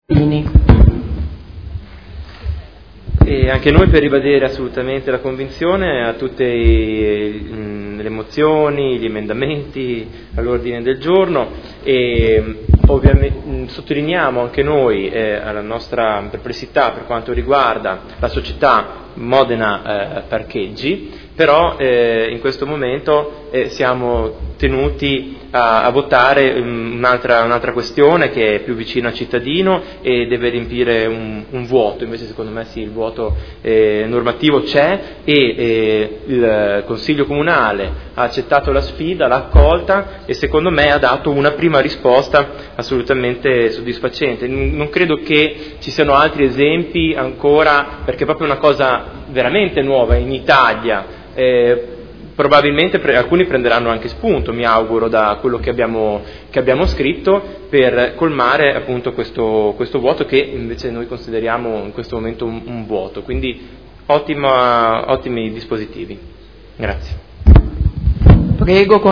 Seduta del 09/07/2015 Dichiarazione di voto. Approvazione del Regolamento per l’utilizzo delle aree di parcheggio a pagamento su strada.